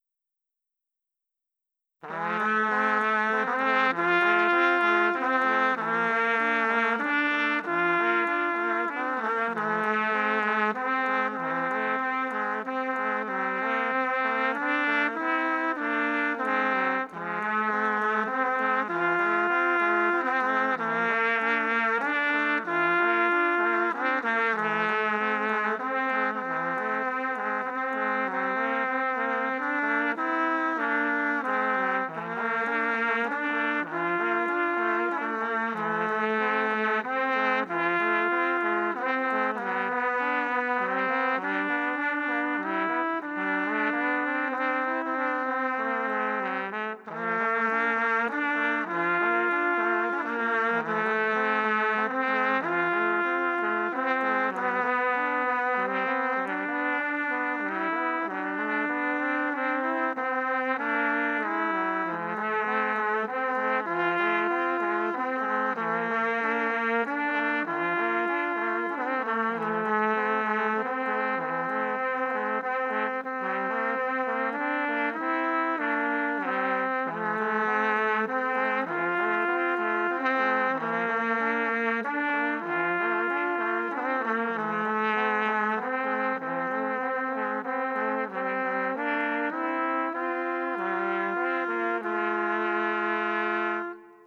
Third Valve Exercise – Trumpet Blog
Due to the fact that we seldom use the third valve, compared to the use of the first and second, we many times face timing and coordination issues. You may find this duet helpful when trying to improve your timing and coordination of your third valve finger.